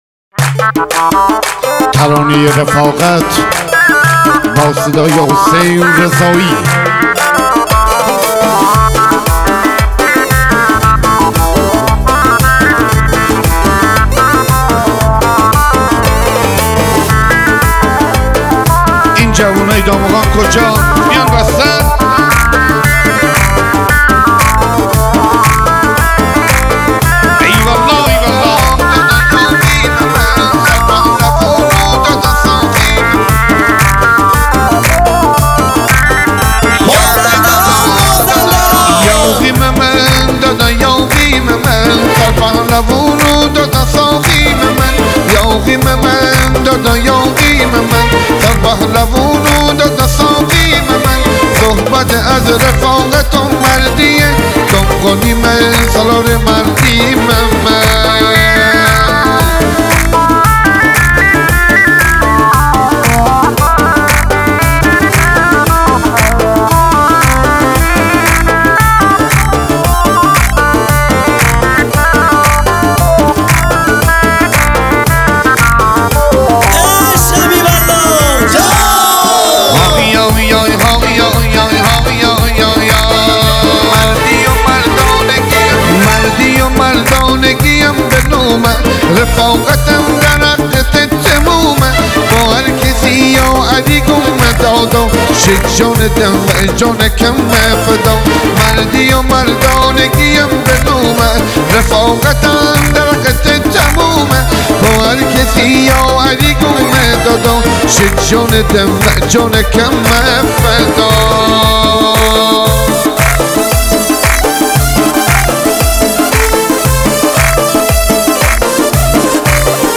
دانلود آهنگ مازندرانی